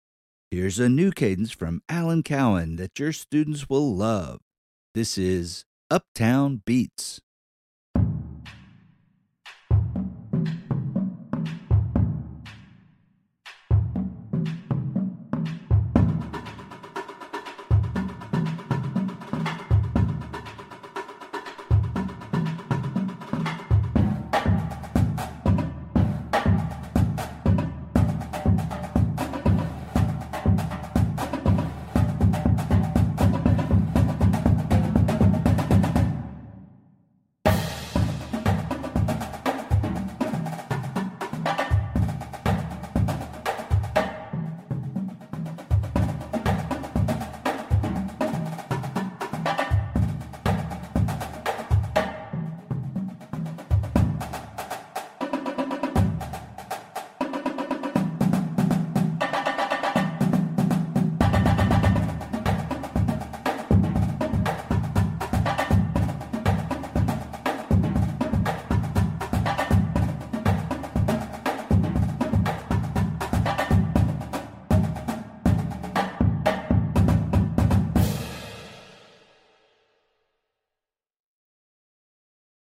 Voicing: Percussion Cadence